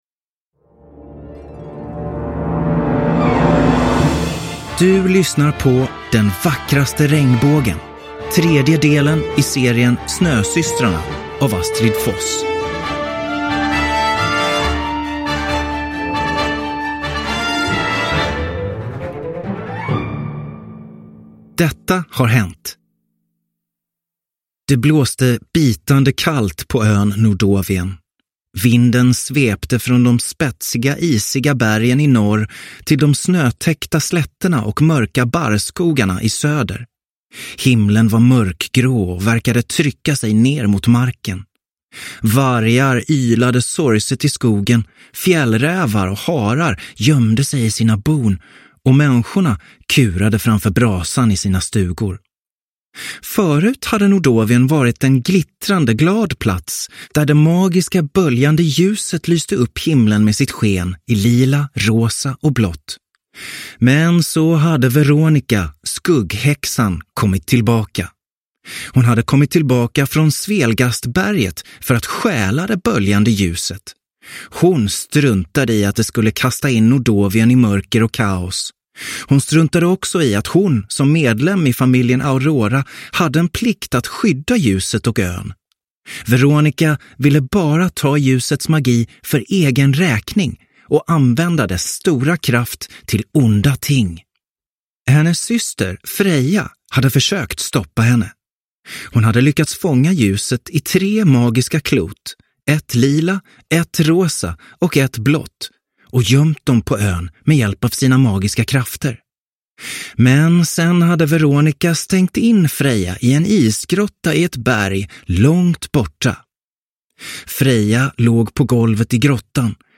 Den vackraste regnbågen – Ljudbok – Laddas ner